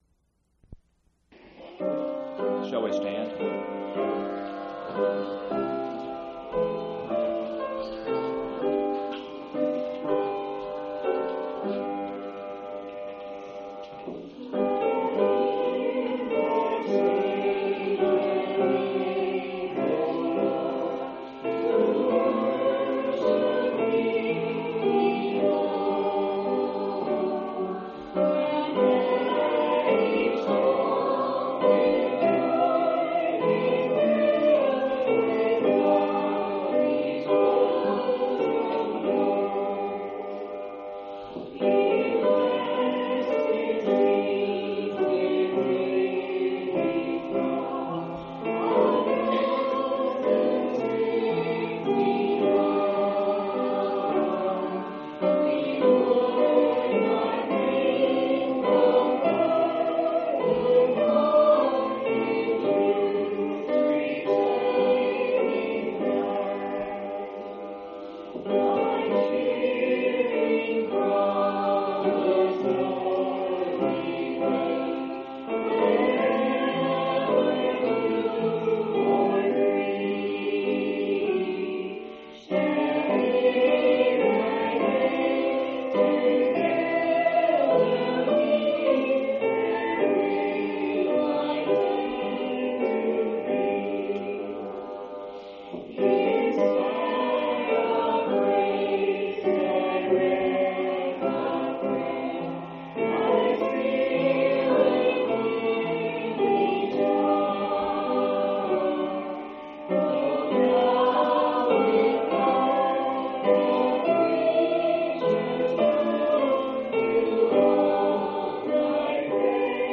10/27/1991 Location: Phoenix Local Event